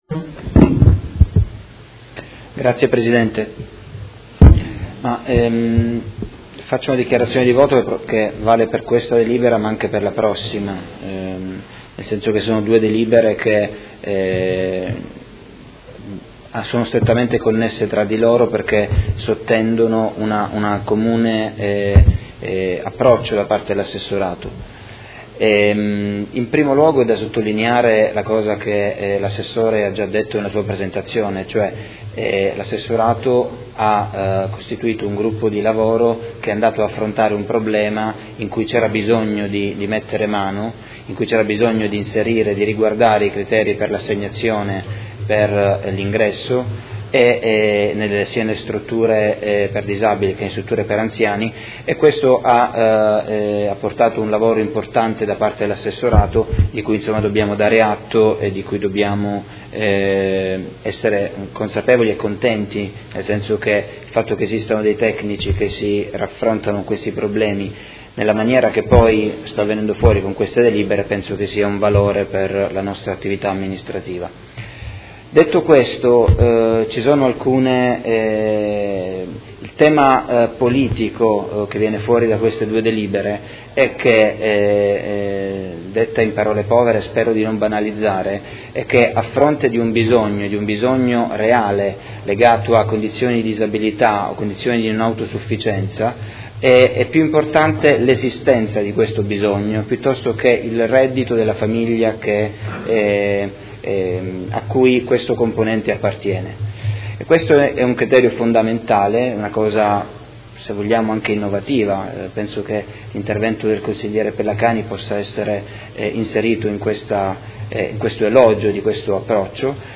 Seduta dell'11/05/2017 Dichiarazione di voto. Regolamento comunale per l’accesso alle Case residenza e ai Centri diurni per anziani e criteri di contribuzione per concorrere al pagamento del servizio – Approvazione